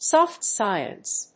アクセントsóft scíence